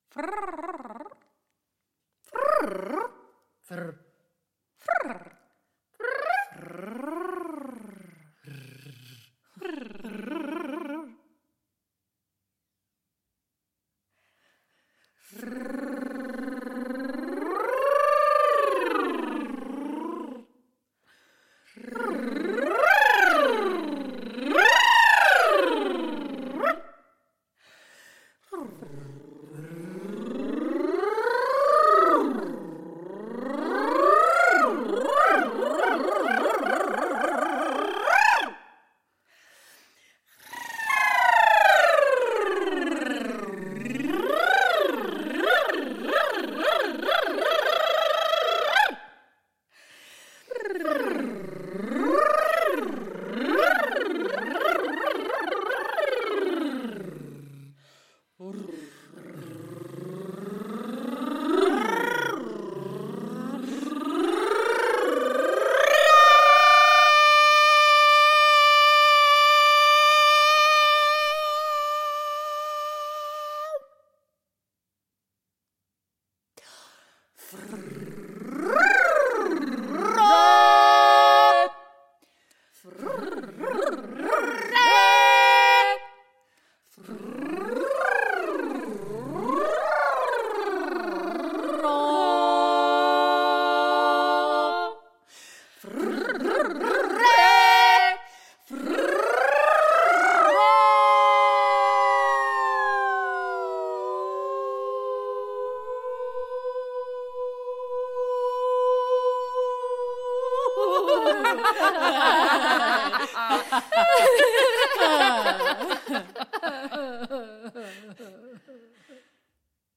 • Genres: Classical, Opera
and avant-garde vocal textures that dip into the absurd
soprano & violin